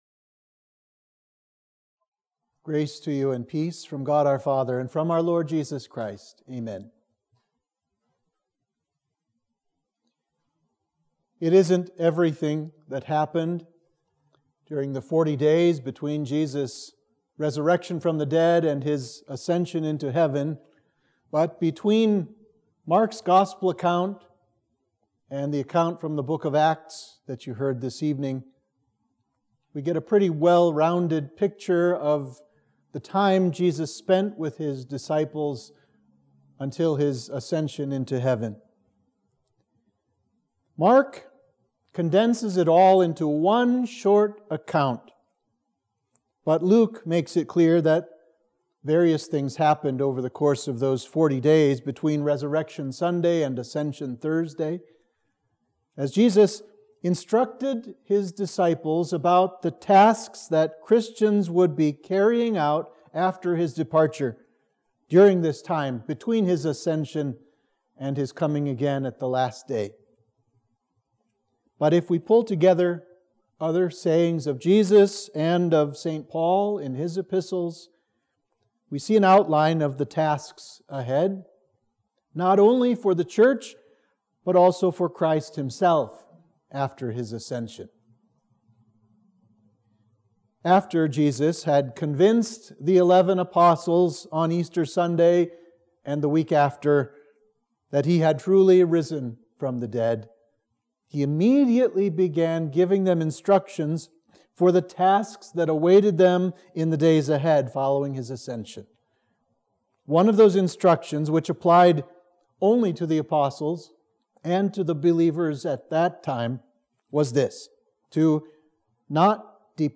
Sermon for the Ascension of our Lord